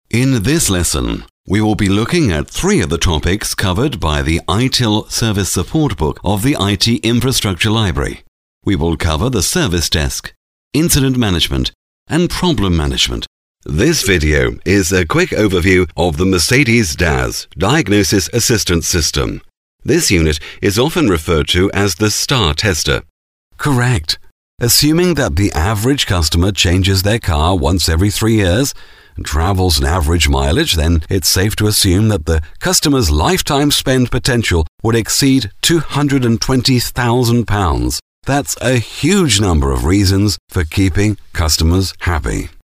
Sprechprobe: eLearning (Muttersprache):
About me: I am a professional voiceover with a rich but clear, resonant, authorative voice. I have a standard English accent, but am very flexible in styles. I have my own broadcast quality studio with a Neumann U87 & TLM 103 microphones and offer fast turnarounds on recording.